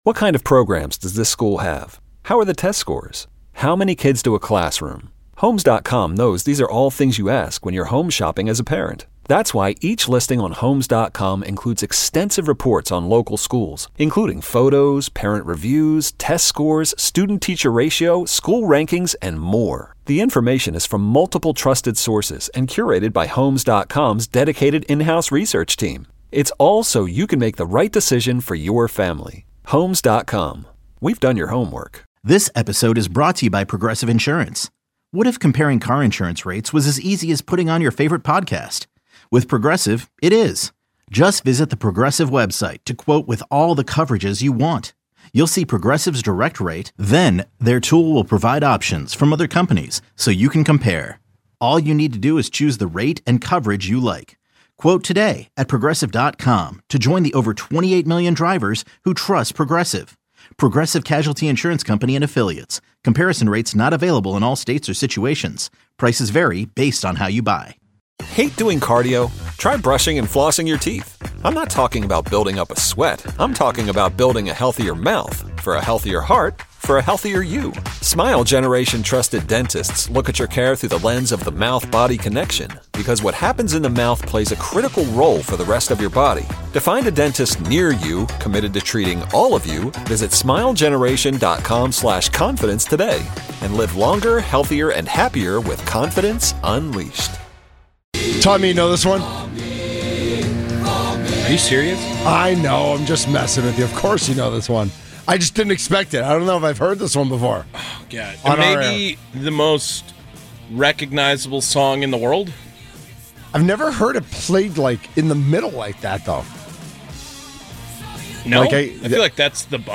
08-28-25 The Mason Crosby Show Interviews